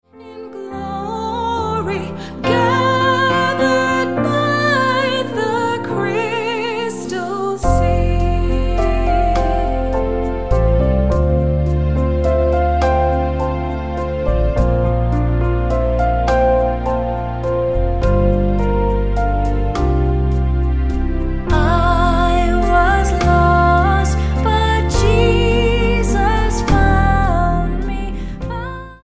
STYLE: MOR / Soft Pop
Pleasant praise and worship.